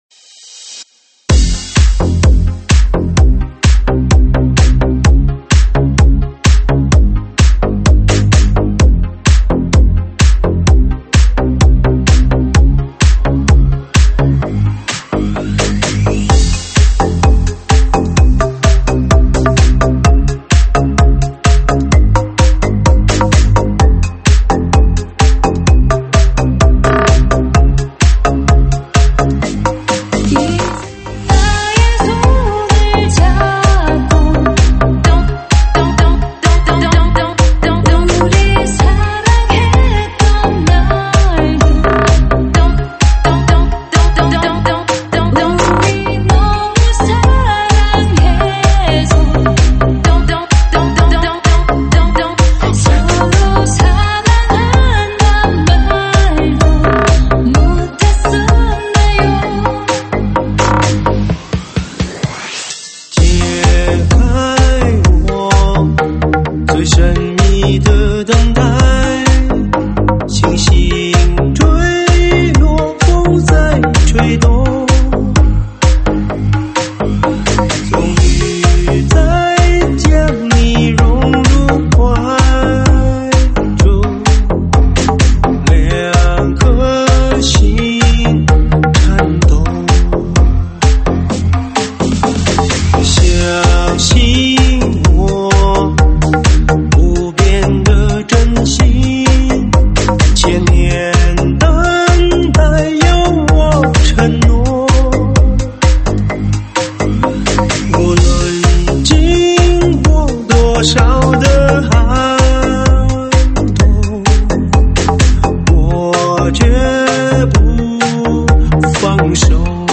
现场串烧